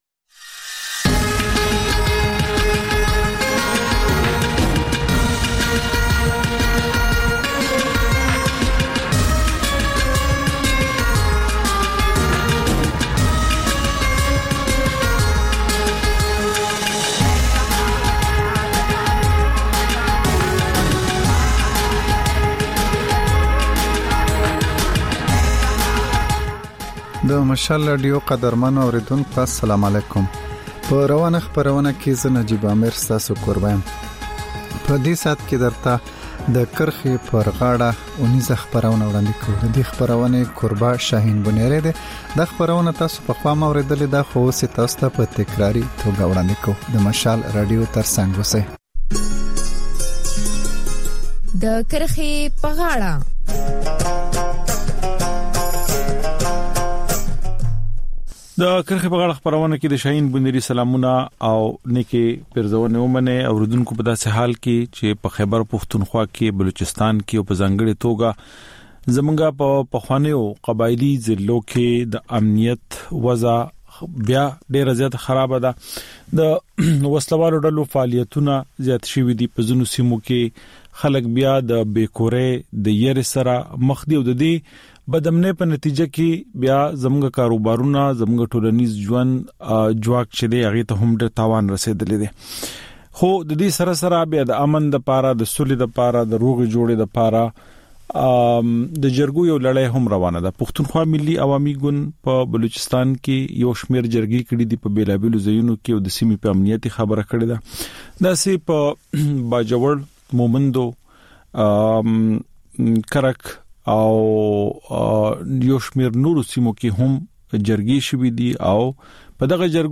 په دې خپرونه کې تر خبرونو وروسته بېلا بېل رپورټونه، شننې او تبصرې اورېدای شئ. د خپرونې په وروستۍ نیمايي کې اکثر یوه اوونیزه خپرونه خپرېږي.